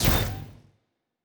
pgs/Assets/Audio/Sci-Fi Sounds/Doors and Portals/Door 8 Close 2.wav at master
Door 8 Close 2.wav